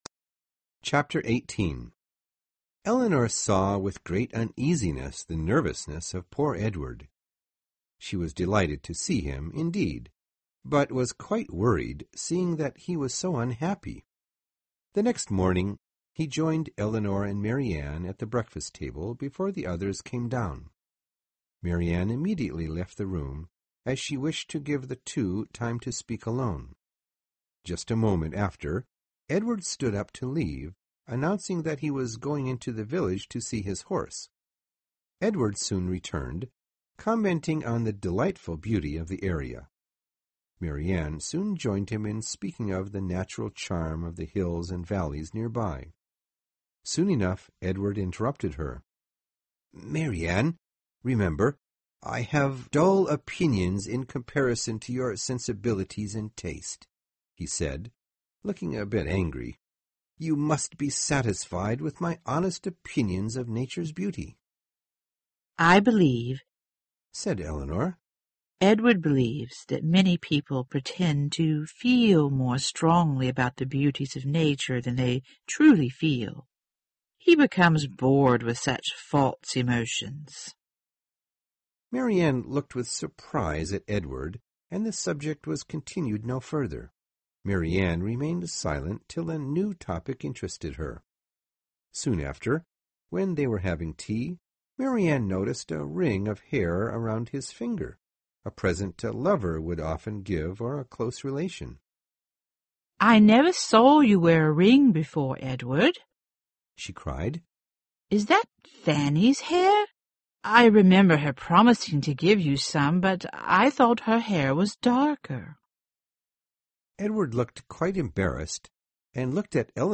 有声名著之理智与情感 18 听力文件下载—在线英语听力室